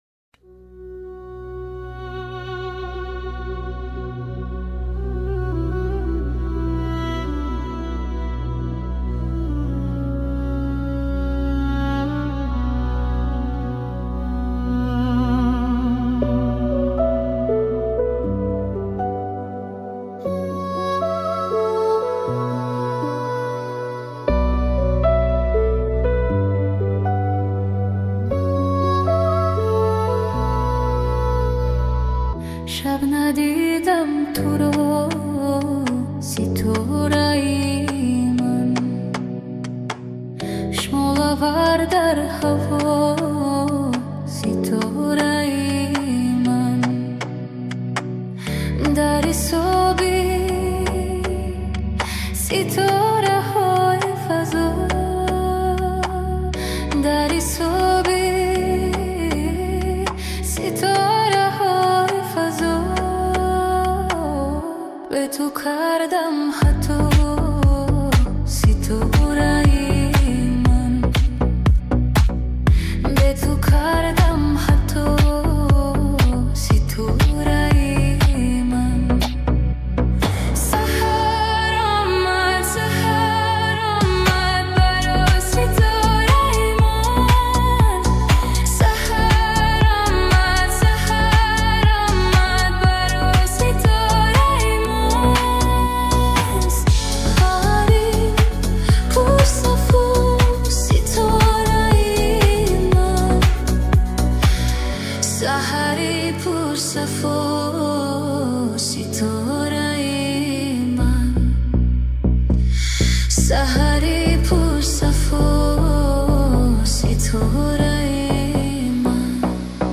Музыка / 2025-год / Таджикские / Клубная / Remix